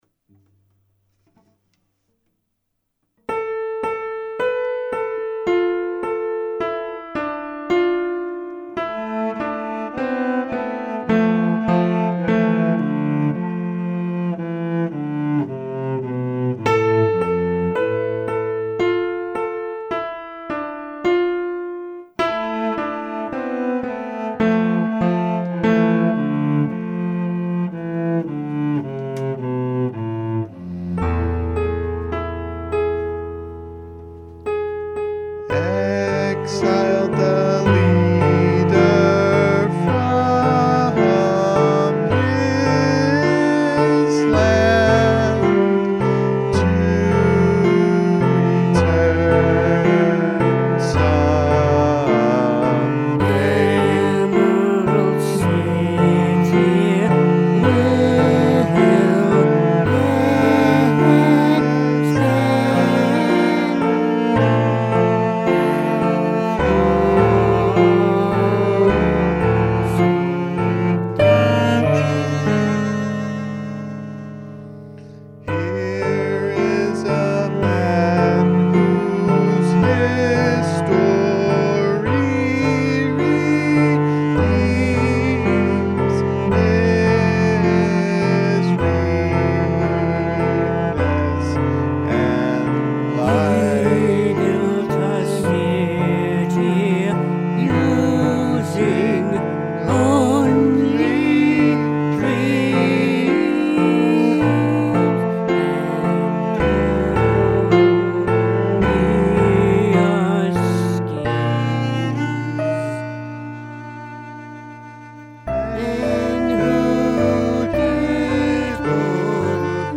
rock opera
'cello